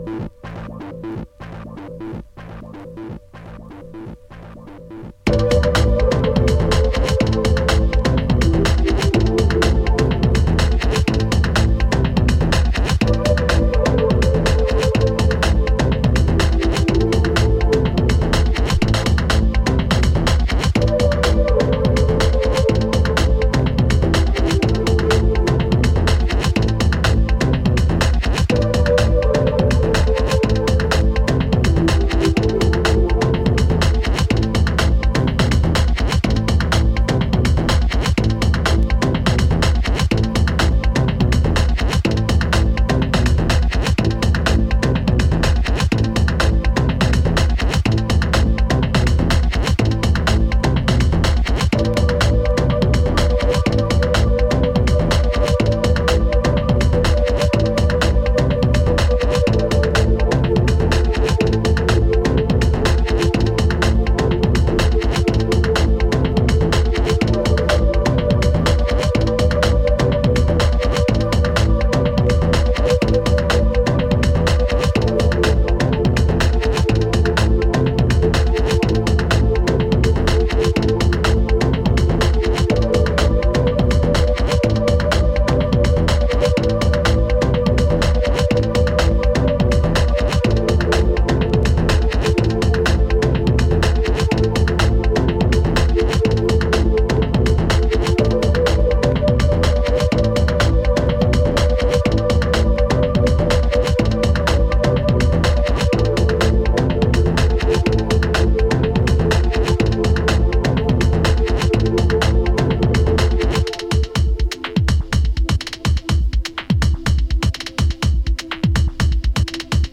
エレクトロニカ/テクノ・ユニット